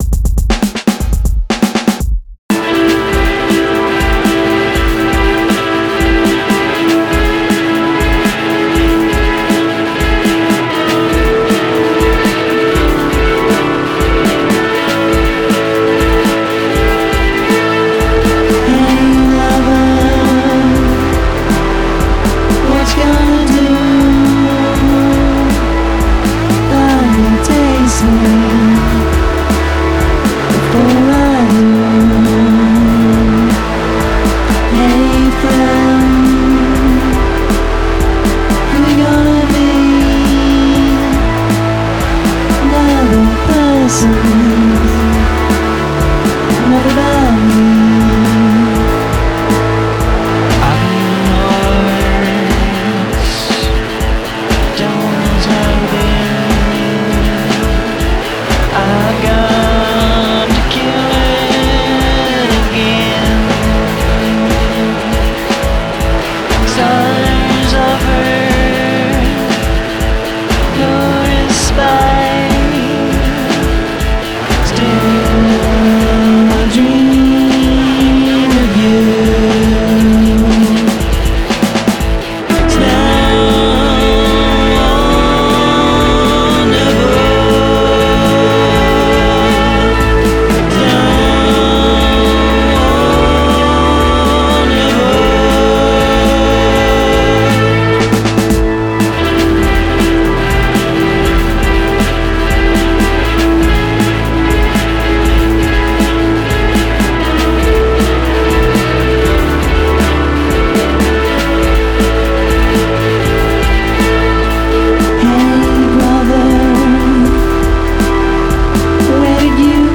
NOISE POP